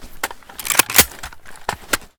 saiga_unjam.ogg